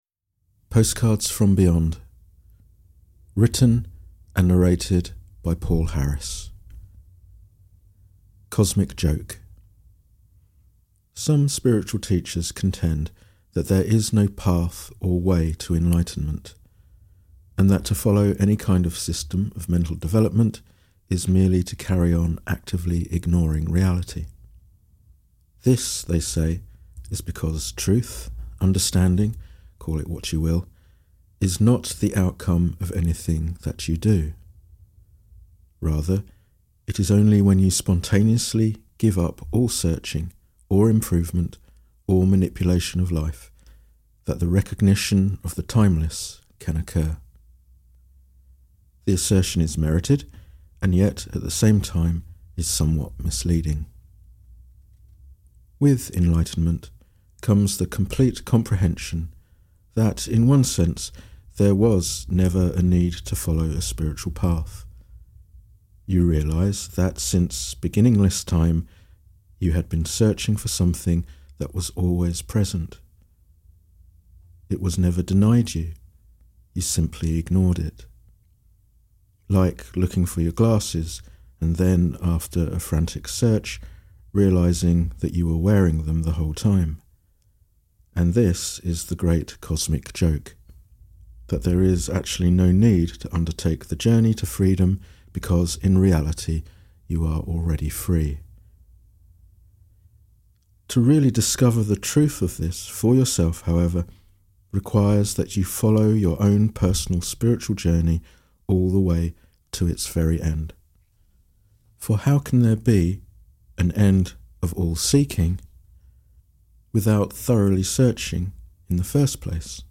Audio recording of the book "Postcards from Beyond"